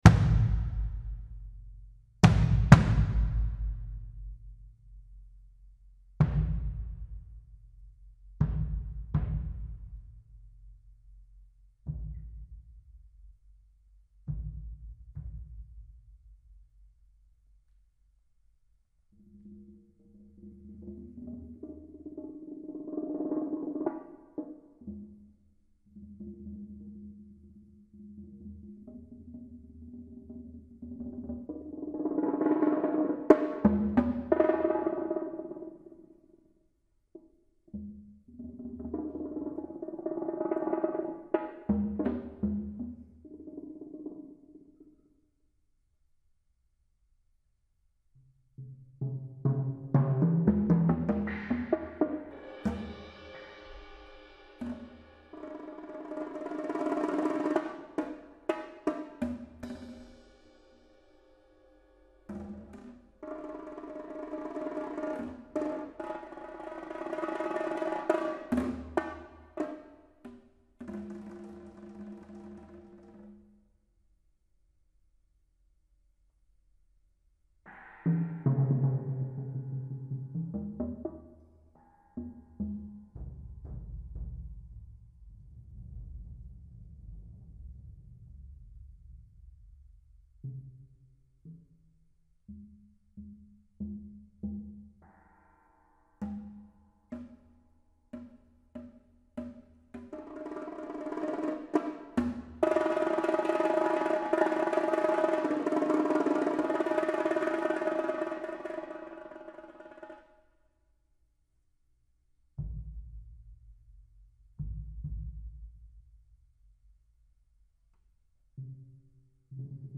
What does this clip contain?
Voicing: Multi-Percussion Unaccompanied